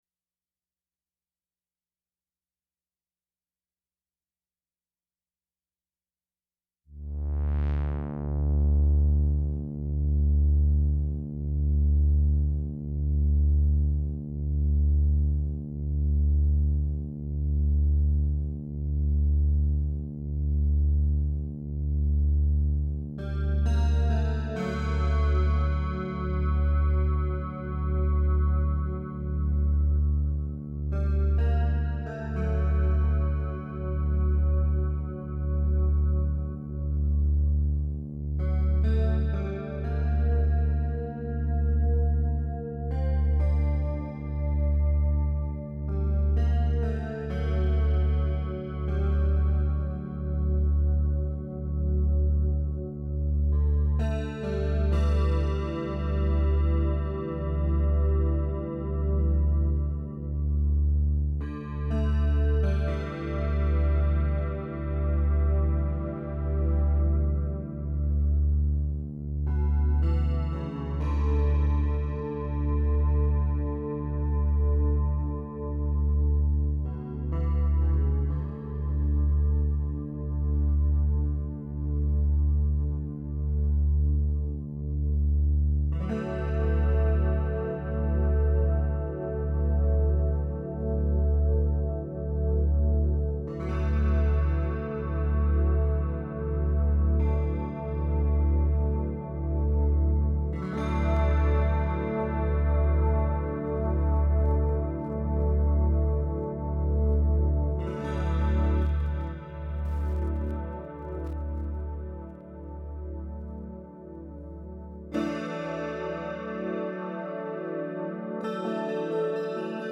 It starts out with a dulcimer sound.
Anchored by a drone. Then what sounds like a waterfall is the arpeggiator playing by itself.
The drone is gone, the anchor is gone.
Slowly the drone turns into a bass line that anchors and structures the piece.
A lead synth comes in augmented by string parts that tend to swell over the mix. It builds with a bit of percussion and then the bass line changes.
A small synth riff is played as everything tapers down removing each part one by one.
The mix thins out leaving the dulcimer sound and the feeling of being adrift.
It’s 2 synthesiser sounds and a lot of knob turning.
It actually starts in the key of G I think.